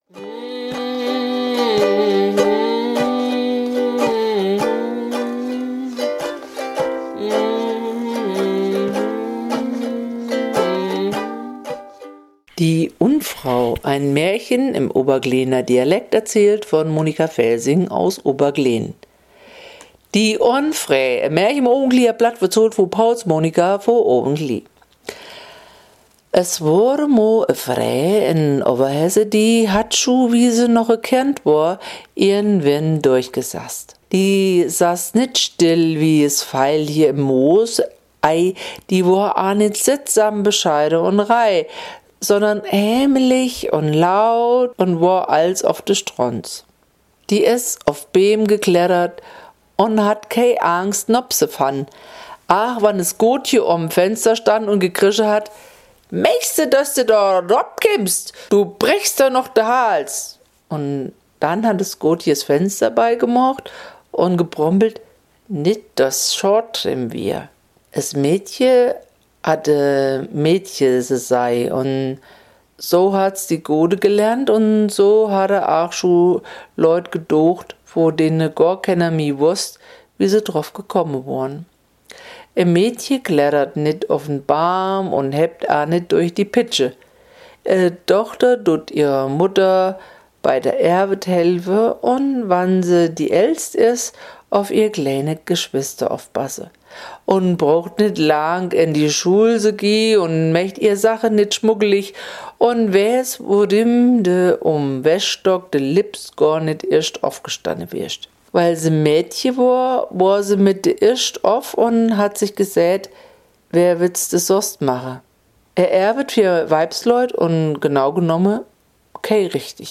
Die Musik ist ein Original, ein bisschen bluesig, improvisiert.